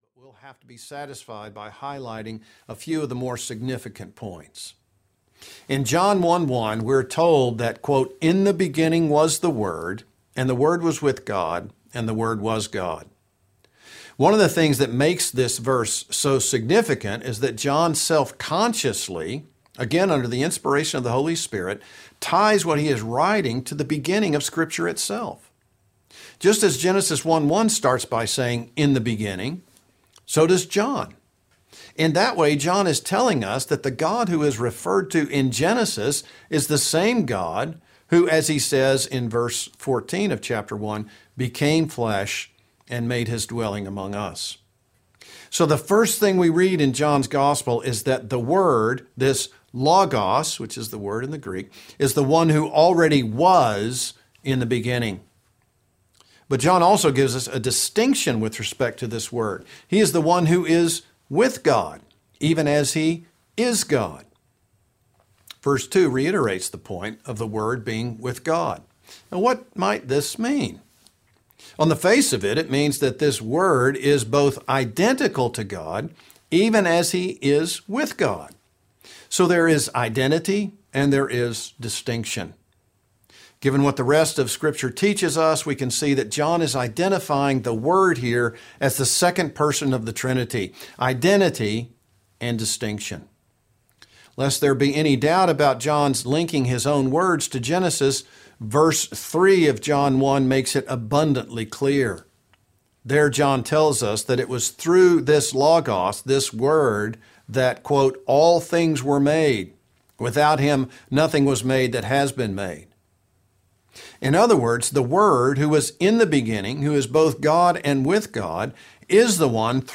Unlike a traditional audiobook’s direct narration of a book’s text, Know Why You Believe: Audio Lectures includes high-quality live recordings of college-level lectures that cover the important points from each subject as well as relevant material from other sources.